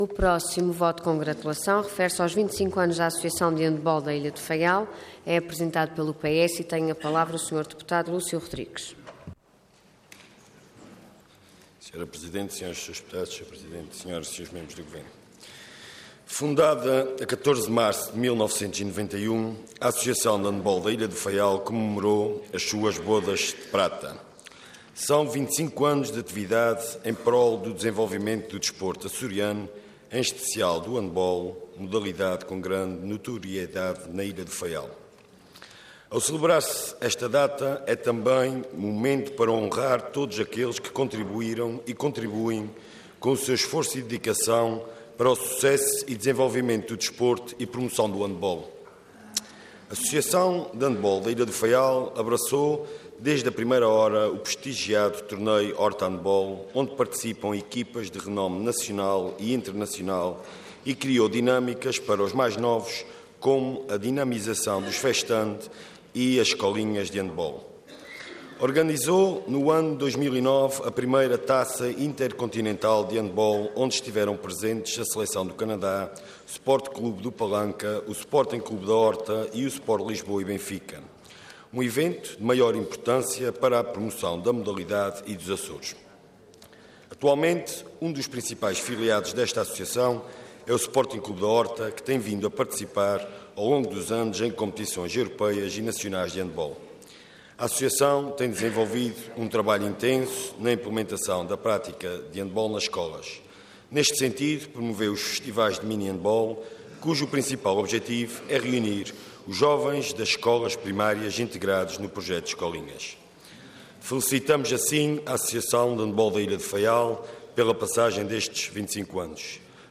Detalhe de vídeo 17 de março de 2016 Download áudio Download vídeo Processo X Legislatura 25 Anos da Associação de Andebol da ilha do Faial Intervenção Voto de Congratulação Orador Lúcio Rodrigues Cargo Deputado Entidade PS